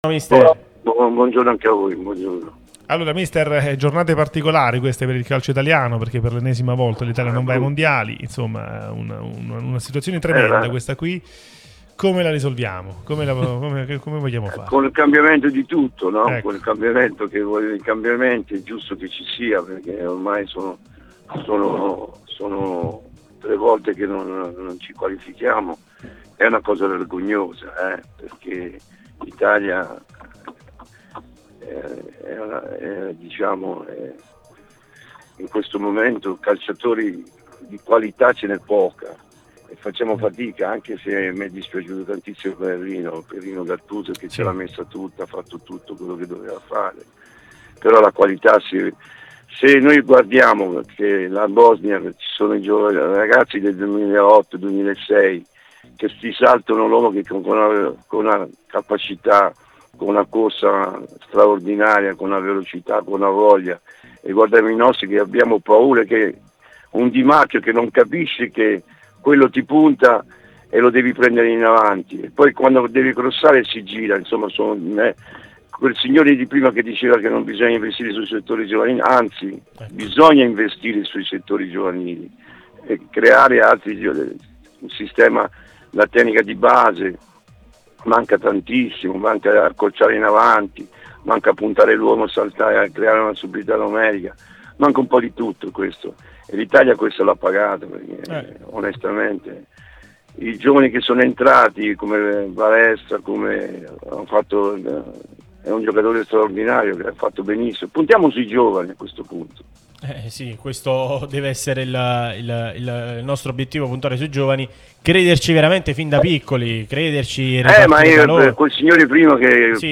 Walter Novellino, ex Napoli e Milan, è intervenuto su Radio Tutto Napoli, l'unica radio tutta azzurra e live tutto il giorno, che puoi seguire sulle app gratuite (per Iphone o per Android, Android Tv ed LG), in DAB o qui sul sito anche in video.